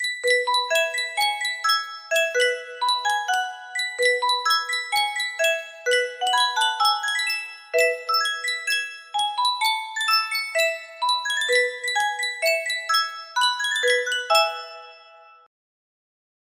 Sankyo Music Box - Camptown Races CH music box melody
Full range 60